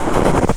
High Quality Footsteps
STEPS Snow, Walk 02-dithered.wav